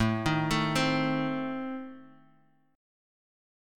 Adim Chord
Listen to Adim strummed